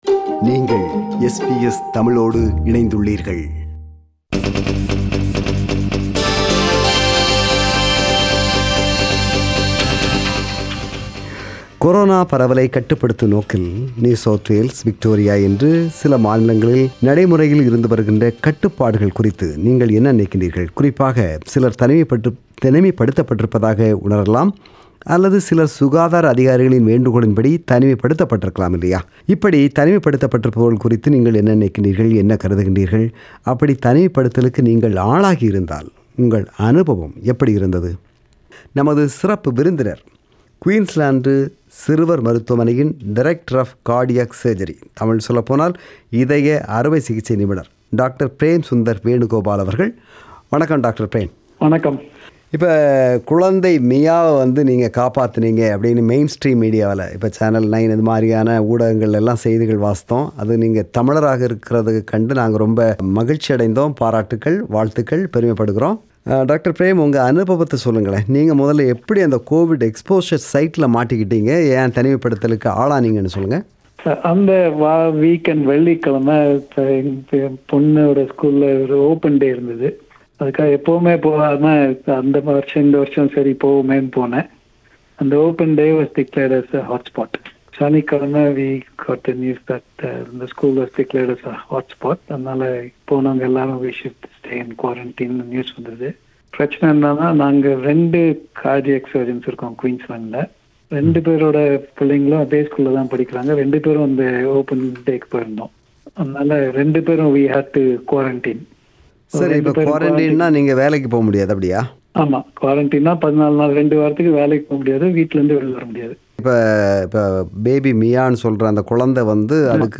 This is the compilation of comments shared in “Vanga Pesalam” program.